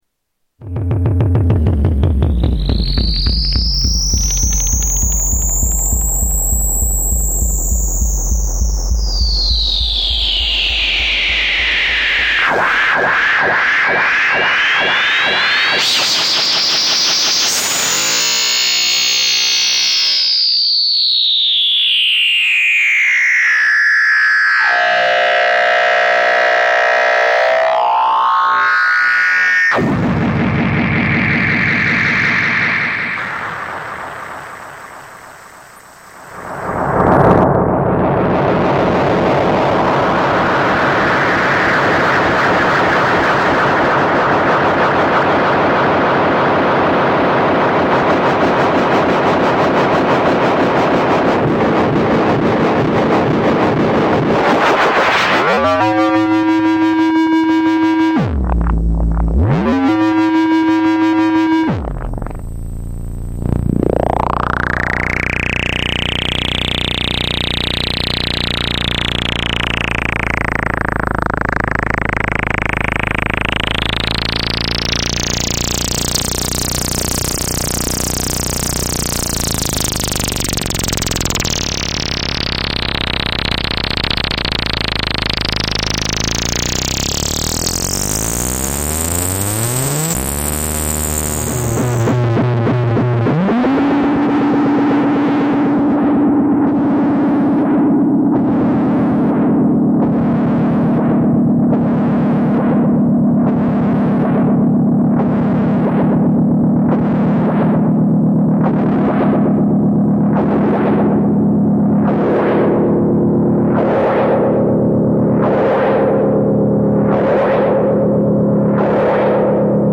Jomox T-Resonator Feedback Loops
Category: Sound FX   Right: Personal